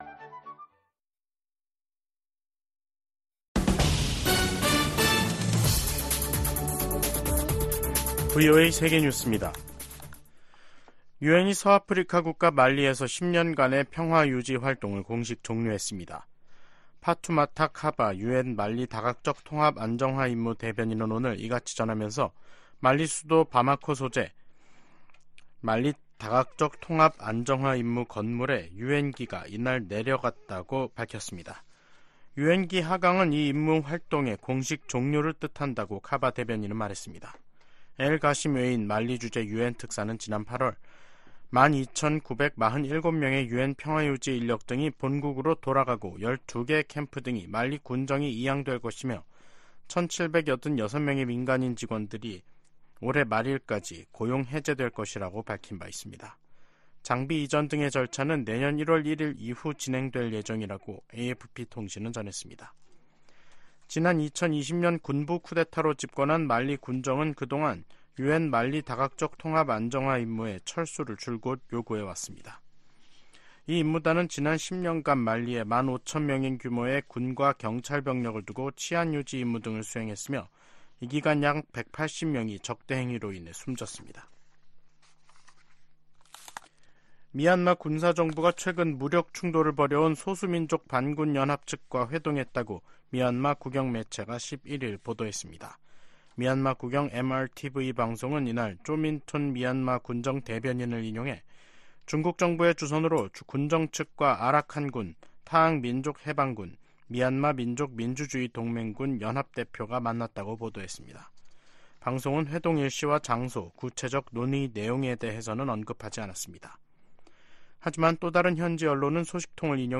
VOA 한국어 간판 뉴스 프로그램 '뉴스 투데이', 2023년 12월 11일 3부 방송입니다. 미국과 한국, 일본이 새 대북 이니셔티브를 출범하면서 북한 정권의 핵과 미사일 기술 고도화의 자금줄 차단 등 공조를 한층 강화하겠다고 밝혔습니다. 미 상하원의원들이 중국 시진핑 정부의 탈북민 강제 북송을 비판하며, 중국의 인권이사국 자격 정지 등 유엔이 강력한 대응을 촉구했습니다. 영국 의회가 북한의 불법 무기 개발과 인권 문제 등을 다룰 예정입니다.